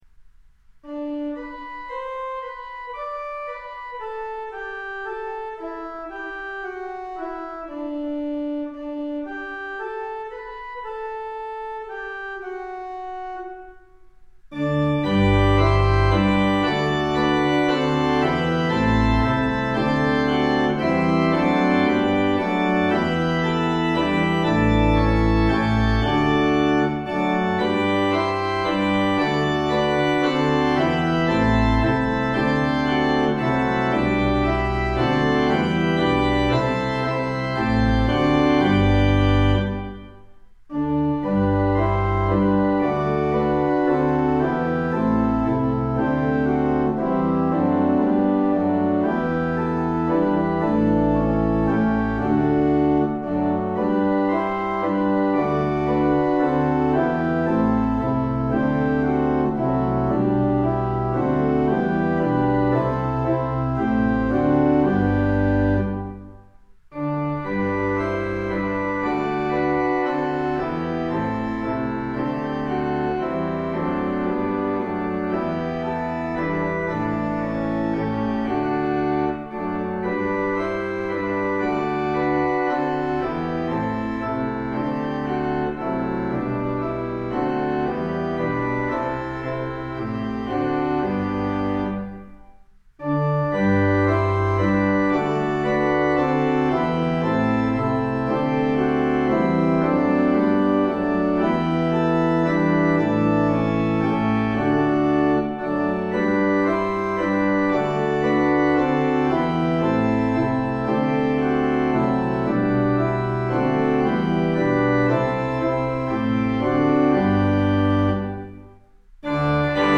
Organ: Moseley